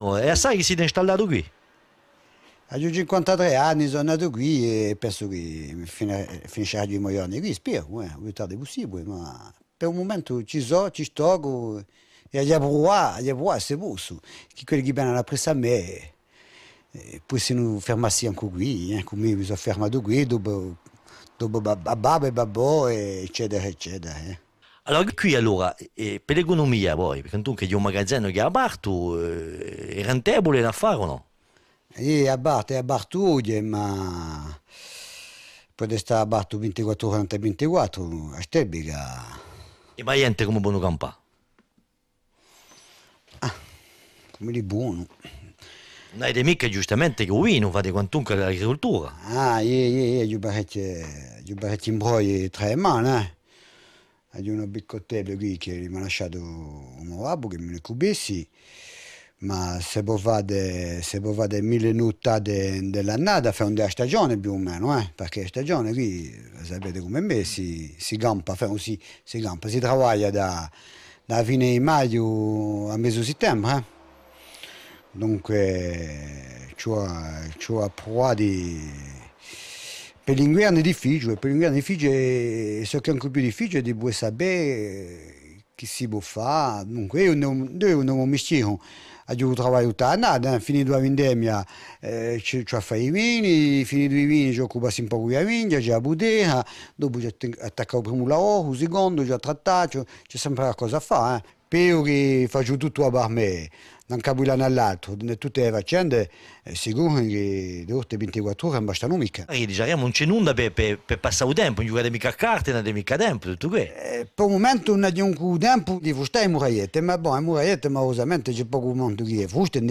Intervista in Balagna
Bande son de la vidéo
SL_intervista_in_balagna.mp3